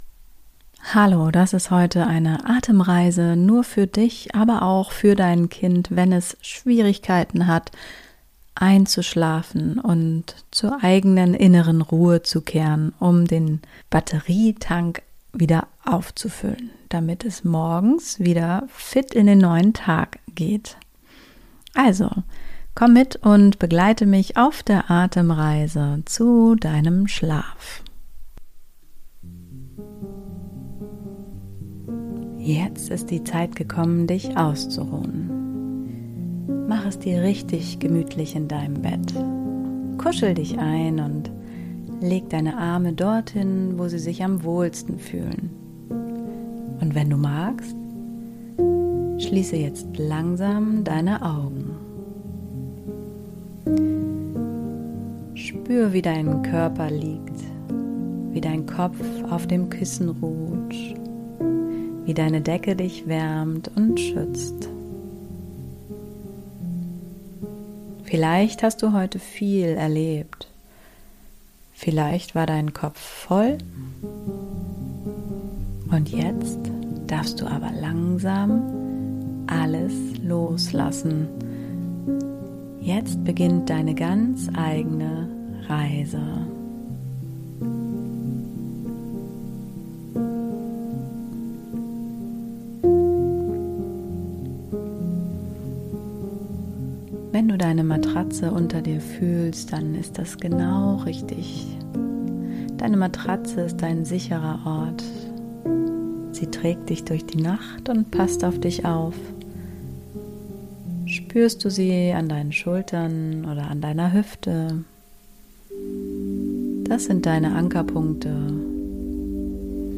Eine magische Einschlafgeschichte mit deinem Tierfreund – für Kinder ab 7 Jahren & Erwachsene